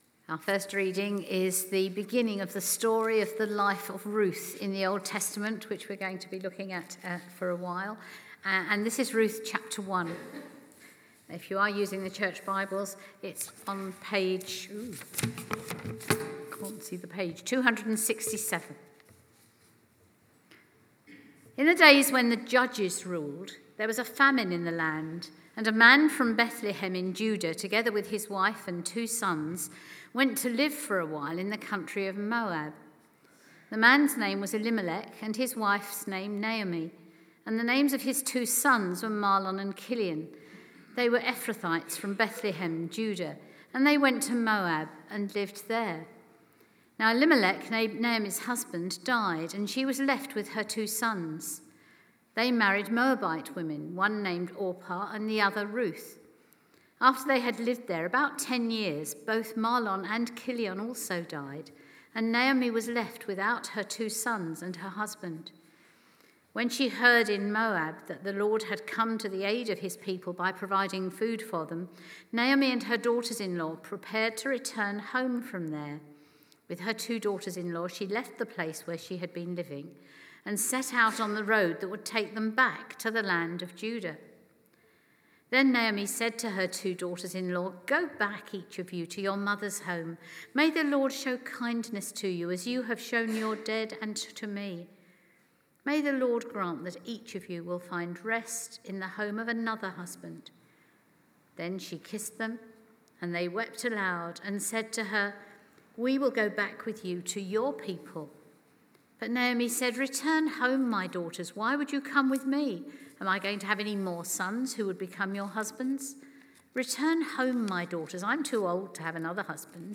Emmanuel Church Sermons